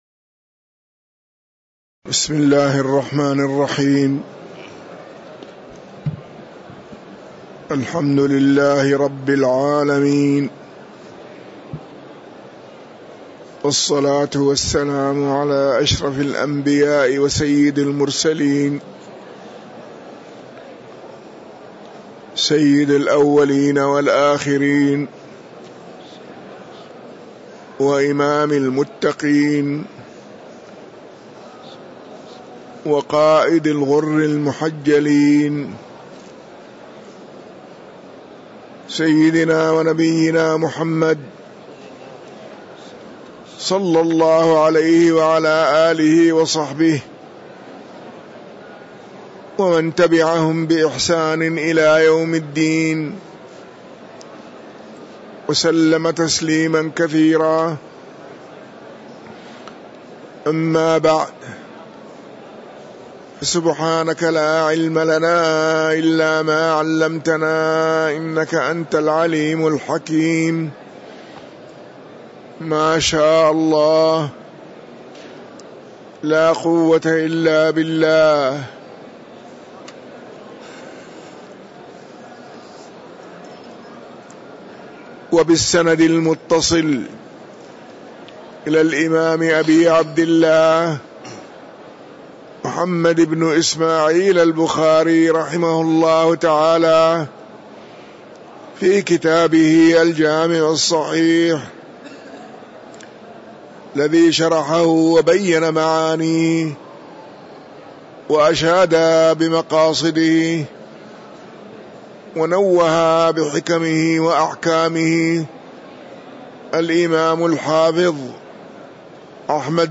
تاريخ النشر ١٠ صفر ١٤٤٤ هـ المكان: المسجد النبوي الشيخ